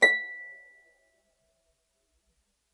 KSHarp_B6_mf.wav